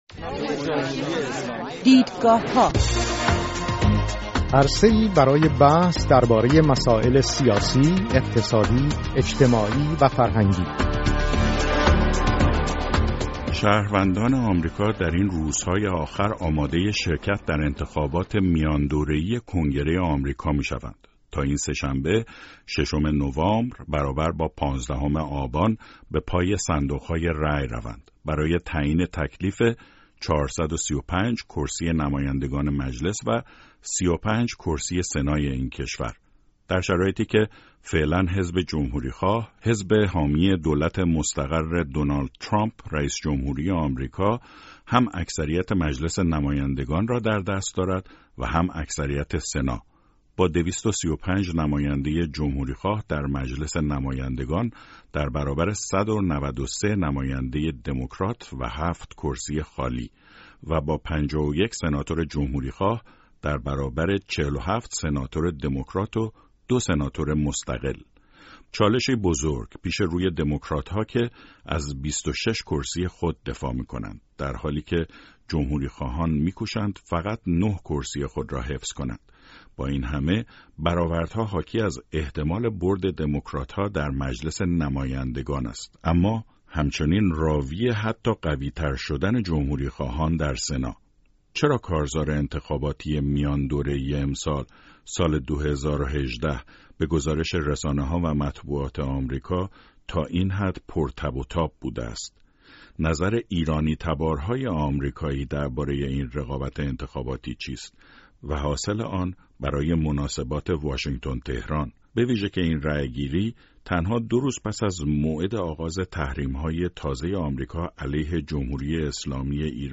مناظره دو ایرانی-آمریکایی درباره انتخابات میان‌دوره‌ای کنگره